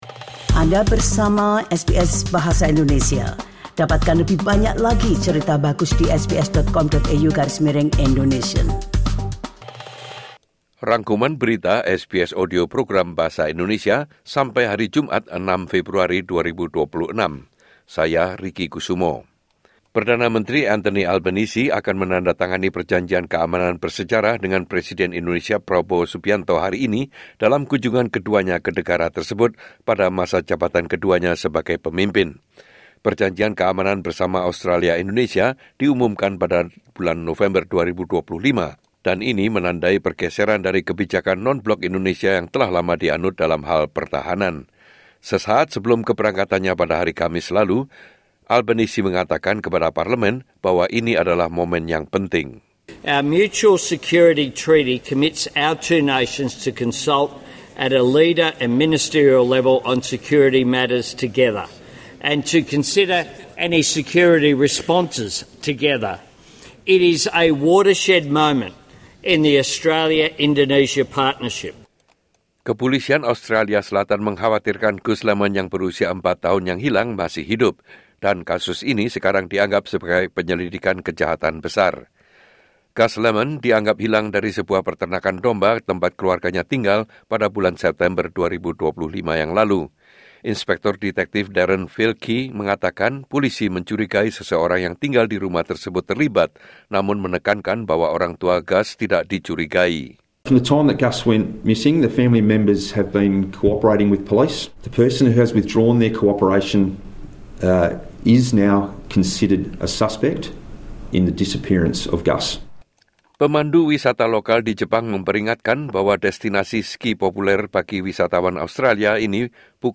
Weekly News Summary SBS Audio Program Bahasa Indonesia - Friday 6 February 2026